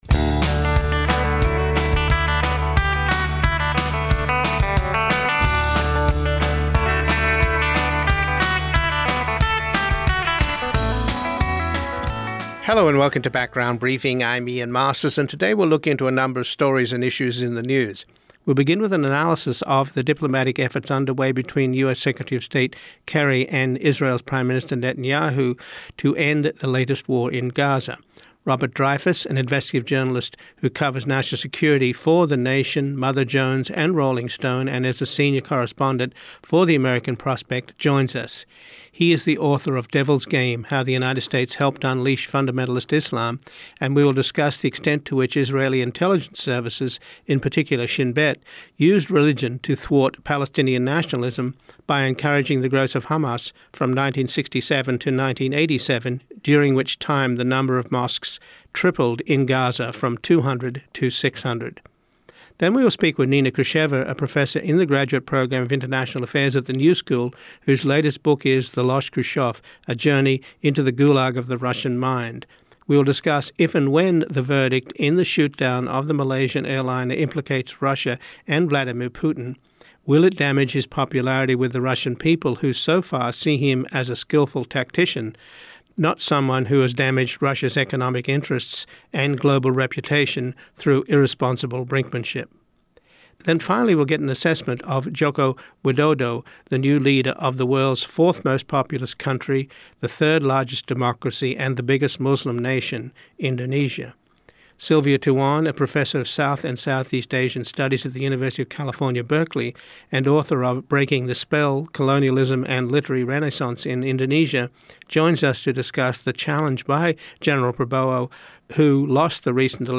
Lawrence Lessig , the Director of the Edmond J. Safra Foundation Center for Ethics at Harvard University and co-founder of the Mayday Super PAC with former George W. Bush adviser Mark McKinnon, joins us to discuss their PAC’s growing crowd-funded war chest and the candidates they are helping in this year’s mid-term election.
Doris Meissner , the former Commissioner of the U.S. Immigration and Naturalization Service under President Bill Clinton, joins us to discuss the fate of any form of immigration reform in this election year in which humanitarian concerns appear to have been trumped by political grandstanding. mp3audio: Full Program